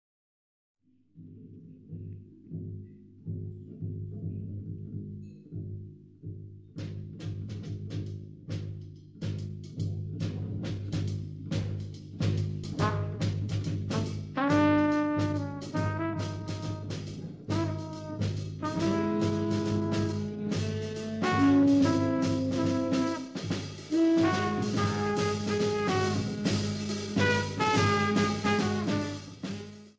piano
saxophones
trumpet
bass
drums